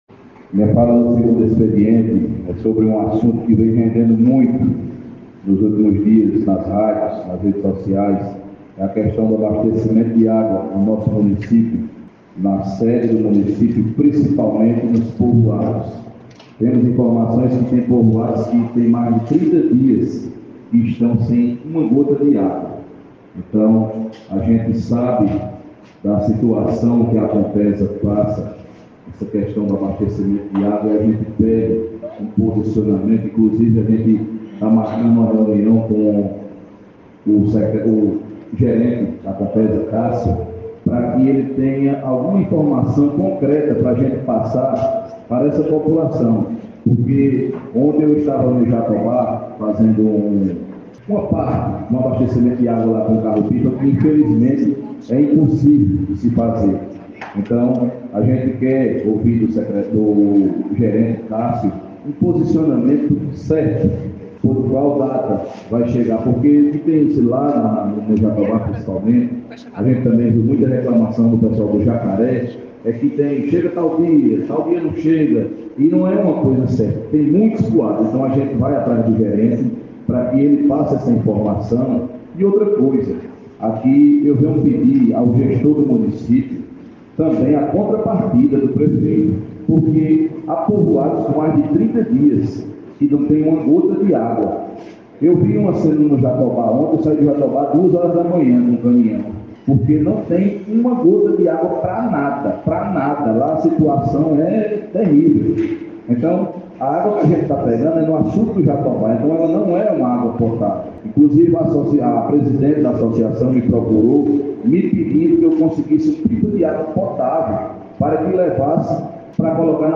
Em sessão ordinária realizado no cine teatro o vereador Rogério da Aldeia usando o espaço do segundo expediente falou sobre a dificuldade encontrada no município de Ouricuri para o abastecimento d’agua no interior.